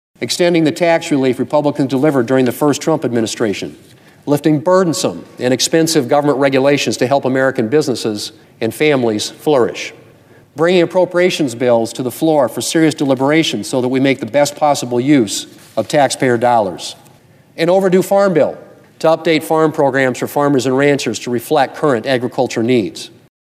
He says many parts of his aggressive agenda to begin this session of Congress are important to producers and rural areas.